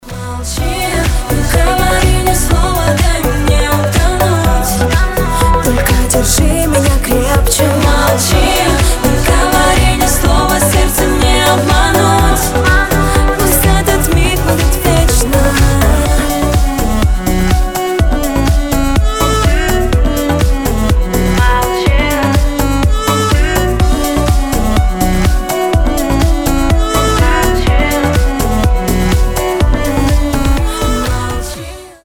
• Качество: 320, Stereo
поп
громкие
женский вокал
восточные мотивы